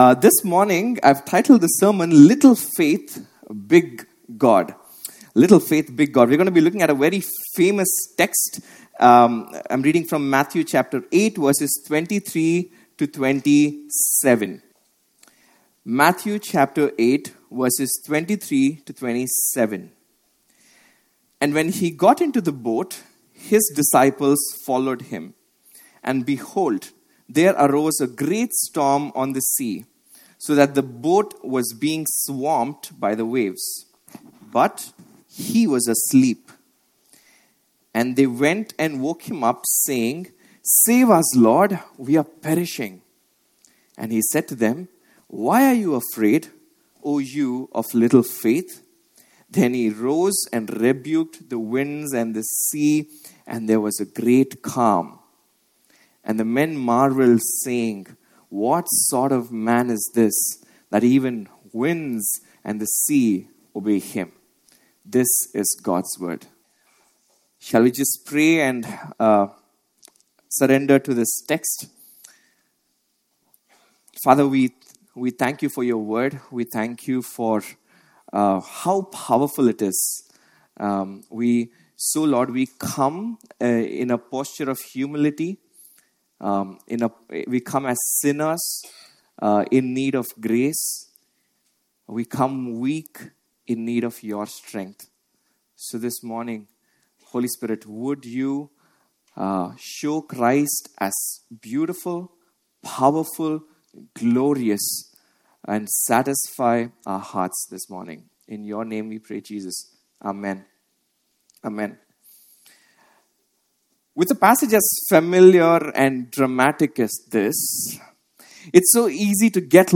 From Series: "Explore Sermons"